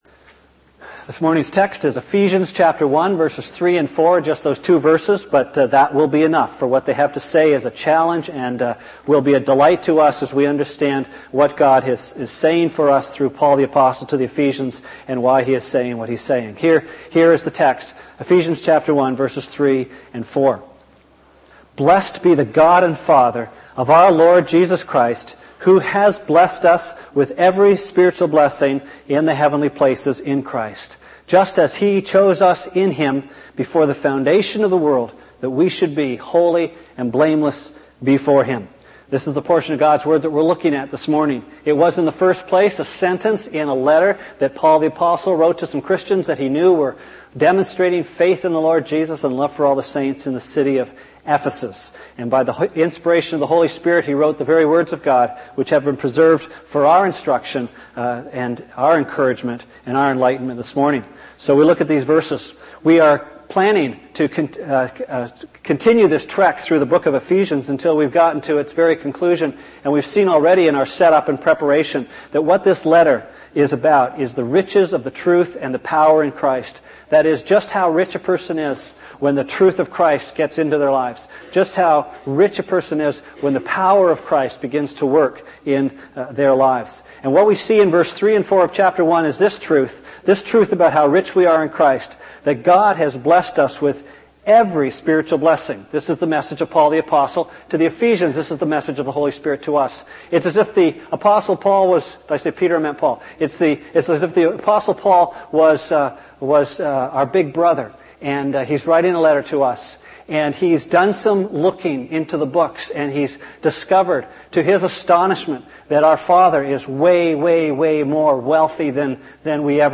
Sermon Archives - West London Alliance Church